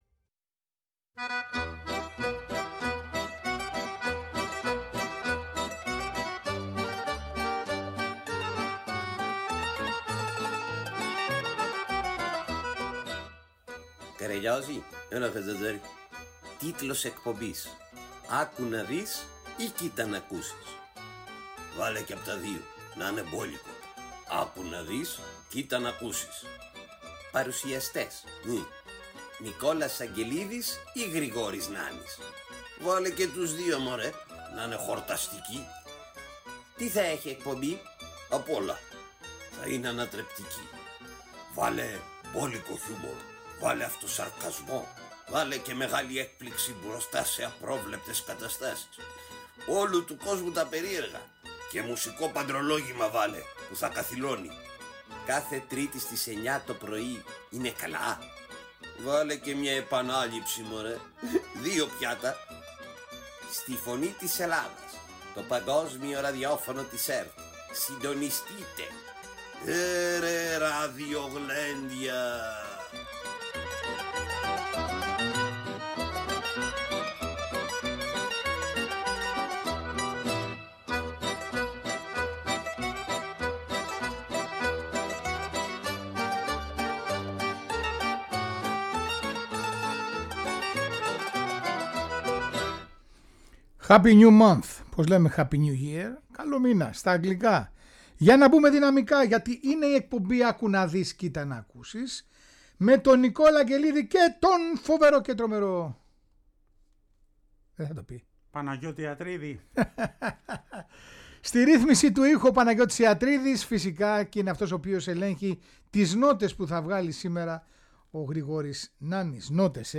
Τέλος μαθαίνουμε την ιστορία και ακούμε τον ύμνο του Κυπριακού ερασιτεχνικού σωματείου Απόλλων Λυμπιών